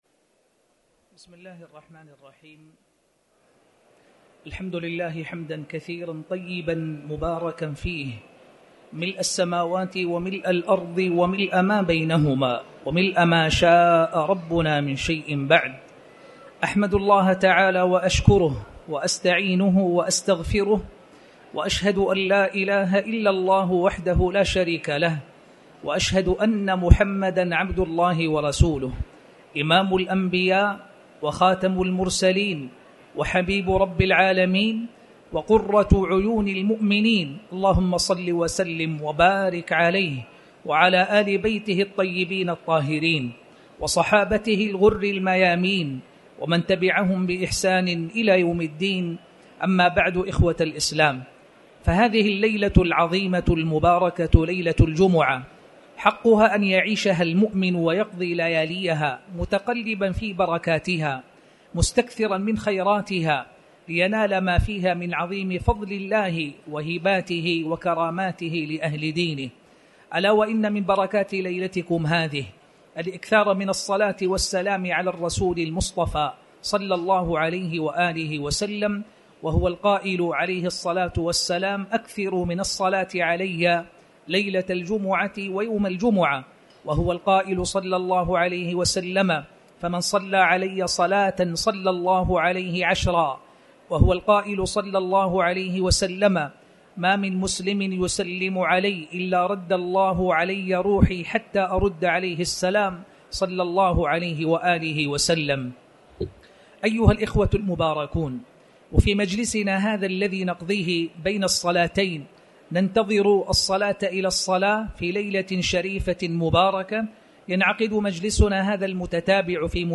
تاريخ النشر ٣ شعبان ١٤٣٩ هـ المكان: المسجد الحرام الشيخ